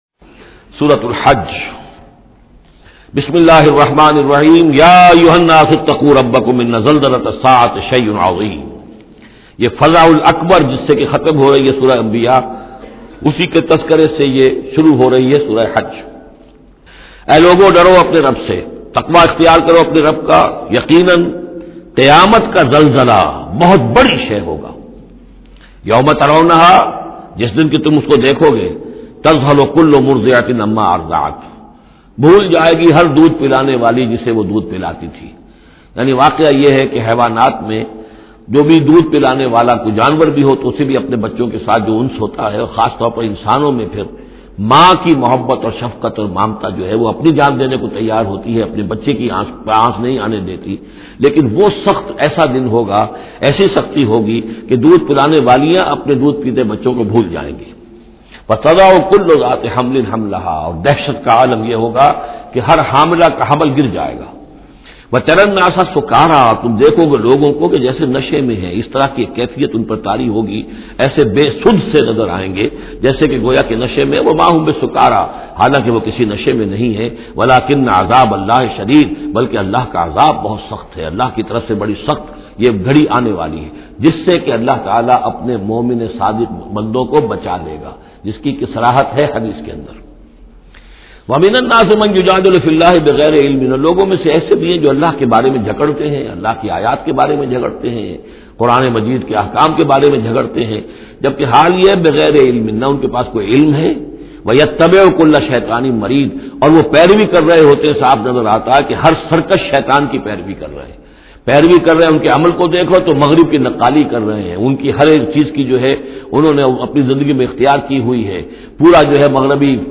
Surah Al-Hajj Tafseer by Dr Israr Ahmed
Surah al Hajj is 22nd chapter of Holy Quran. Listen online and download urdu tafseer of Surah Al Hajj in the voice of Dr Israr Ahmed.